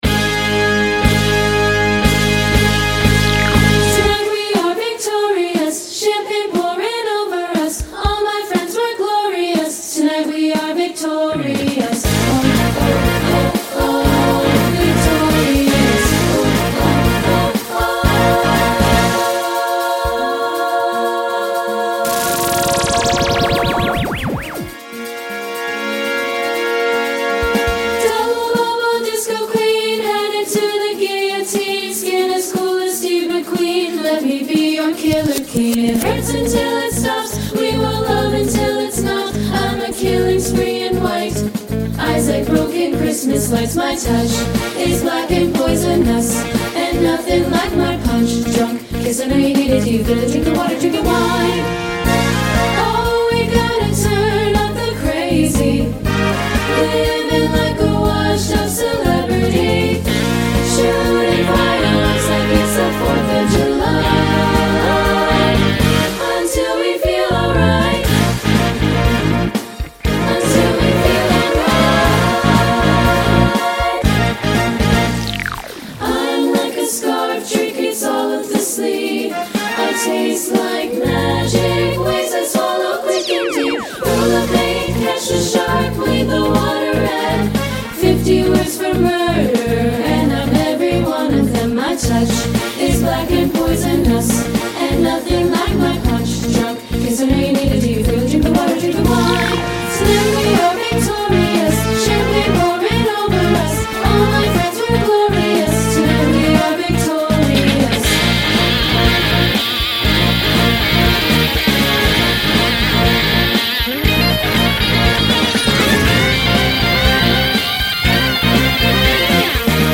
SSA Full Performance recording